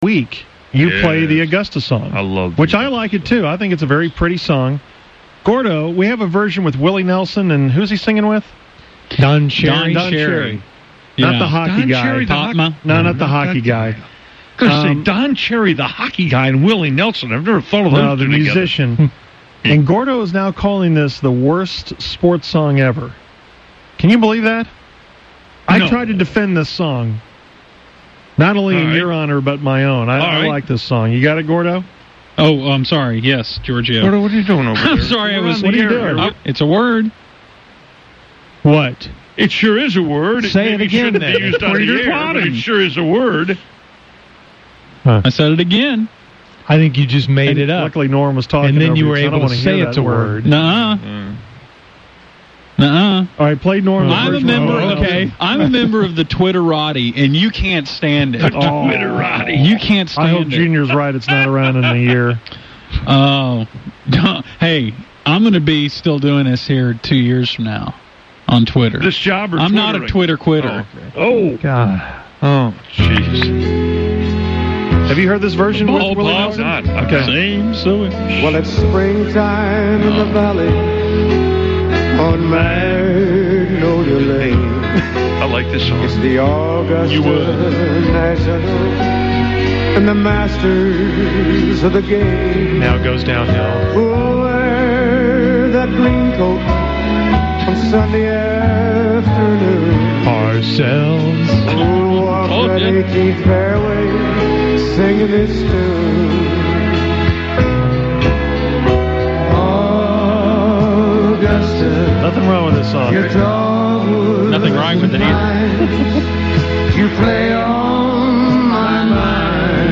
Expect to hear from the fake Ronnie Reagan.